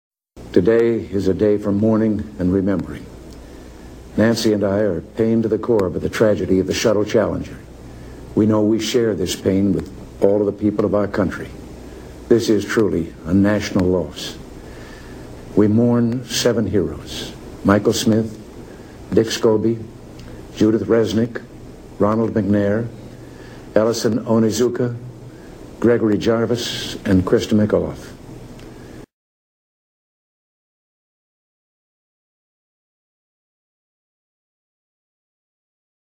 The Compact Disc contains speeches that should be played through large speakers in the concert hall.